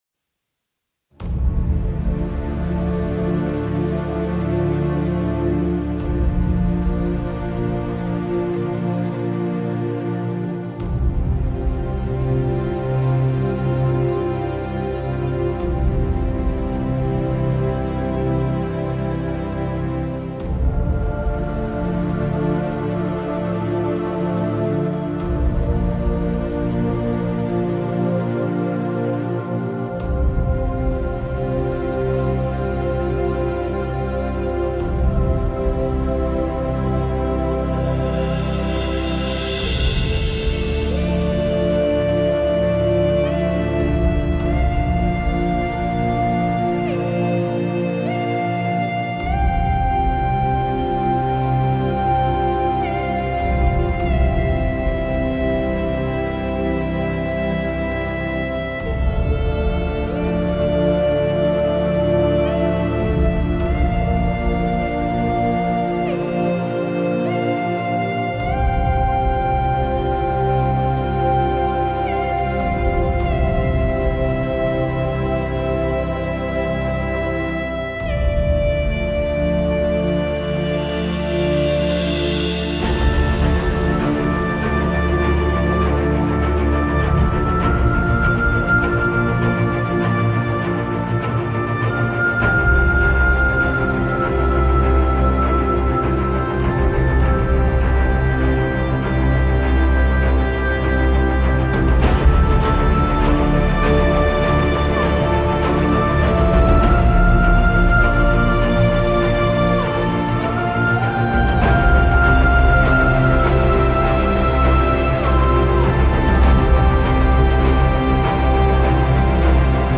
fantasy_music_-_lumina_hd_u_.mp3